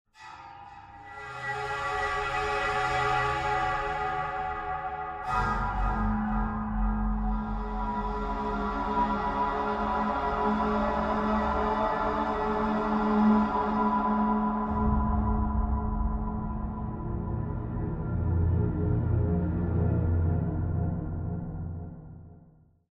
音色试听